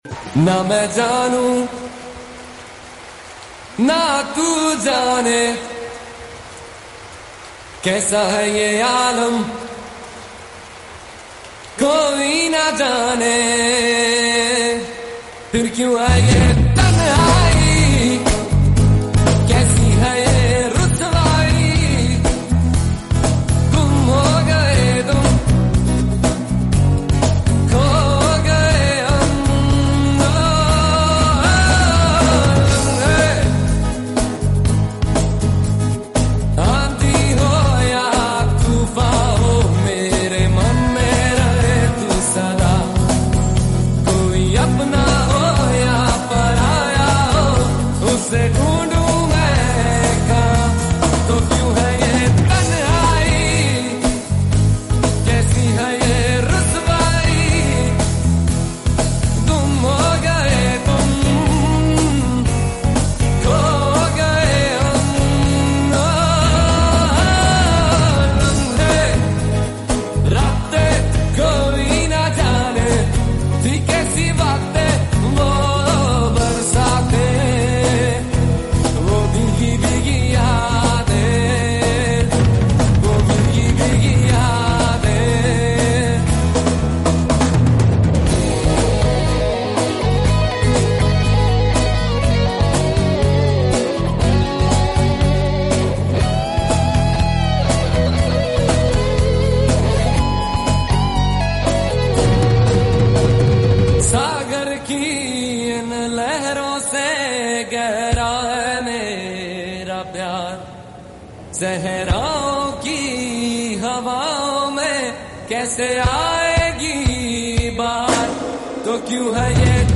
New Year Celebrations LIVE Performance